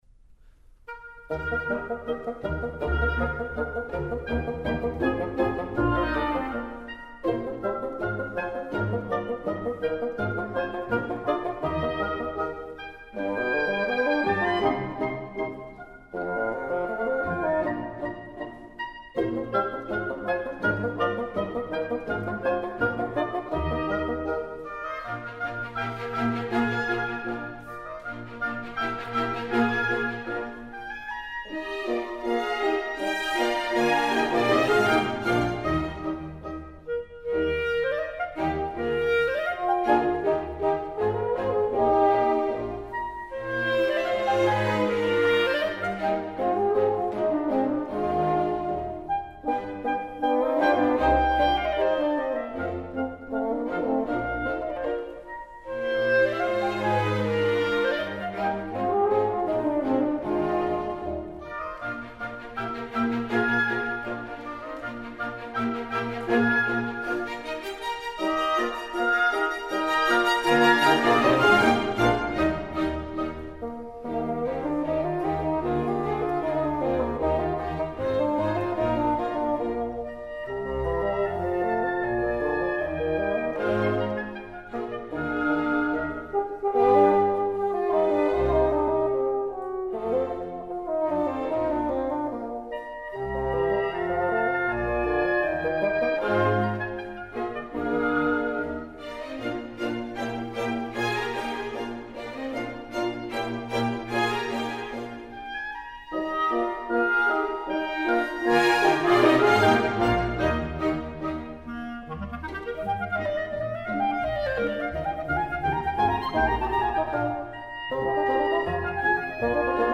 clarinet.
Douglas Boyd, conductor.
Their fluent exchange there marks a highlight of this throughout lucid recording.